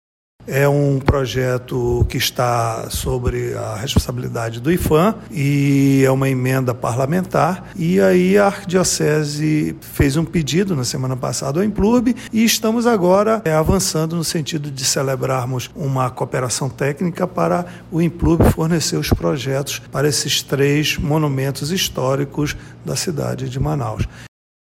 Sonora-2-Carlos-Valente-diretor-presidente-do-Implurb.mp3